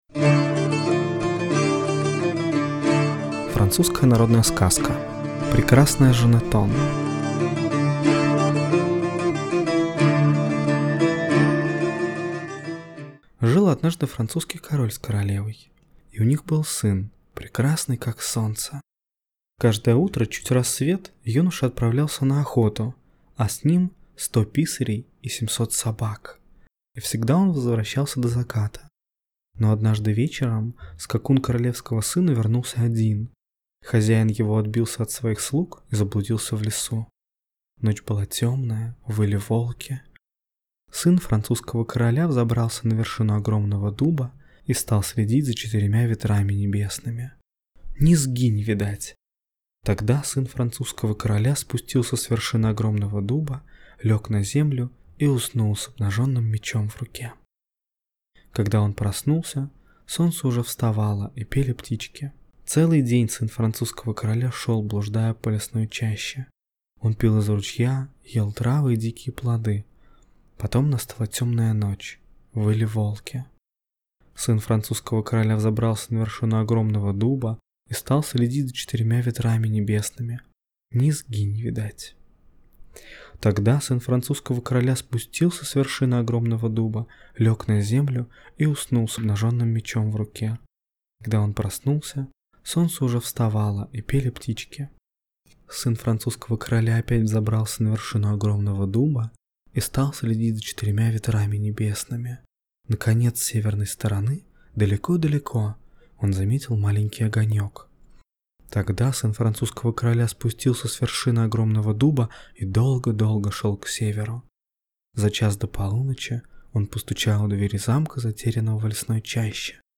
Прекрасная Жанетон - французская аудиосказка - слушать онлайн